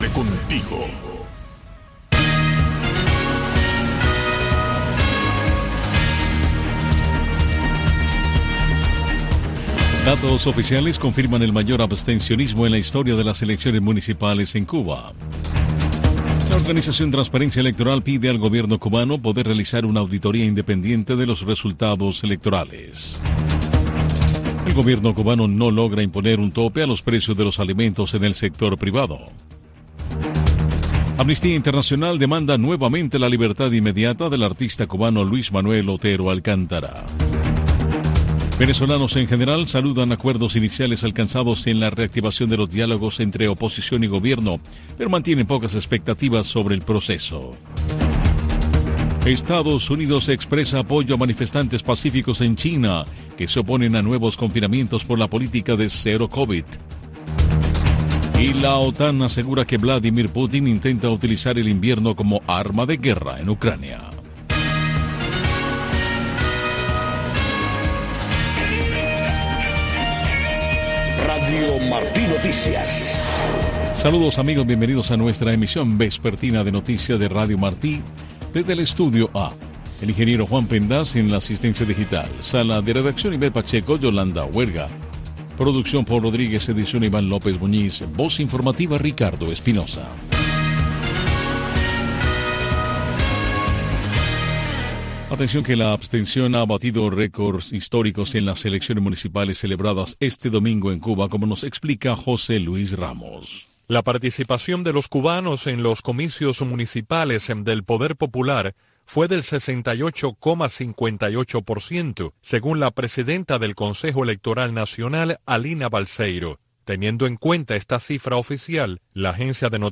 Noticiero de Radio Martí 5:00 PM | Primera media hora